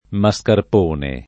vai all'elenco alfabetico delle voci ingrandisci il carattere 100% rimpicciolisci il carattere stampa invia tramite posta elettronica codividi su Facebook mascarpone [ ma S karp 1 ne ] (meno com. mascherpone ) s. m.